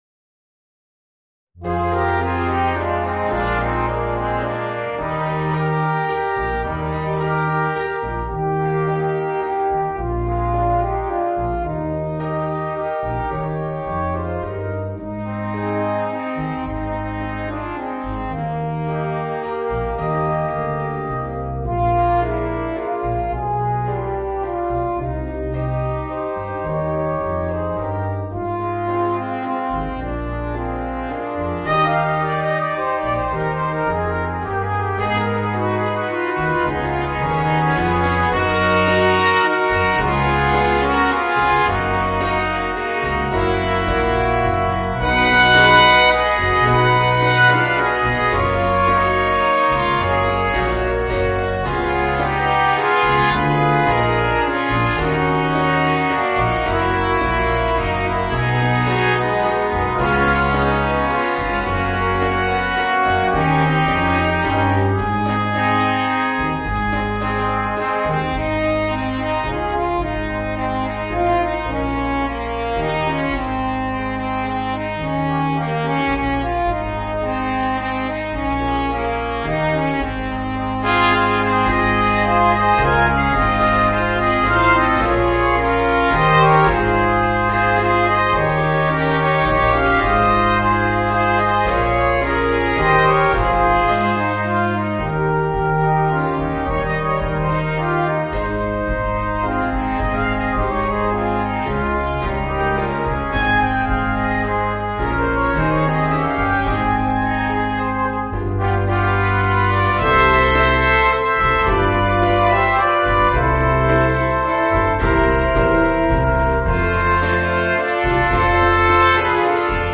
Voicing: 2 Trumpets, Horn and Trombone